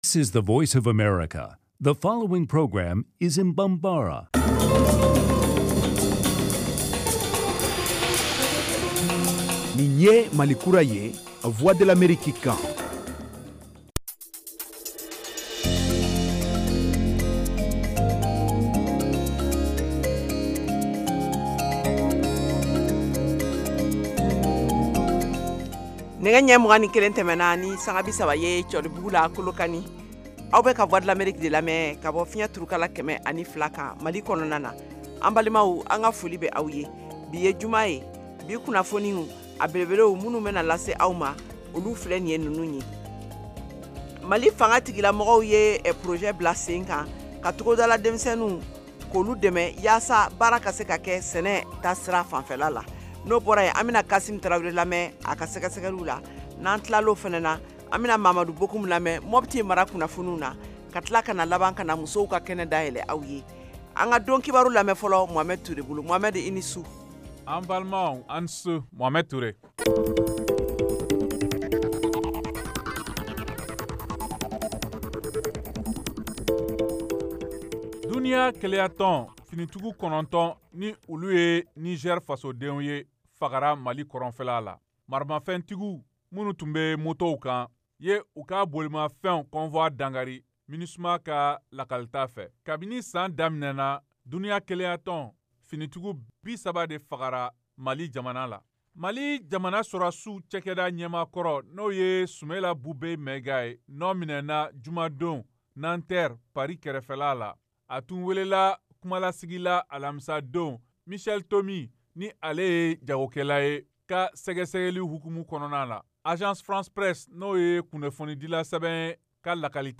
Emission quotidienne
en direct de Washington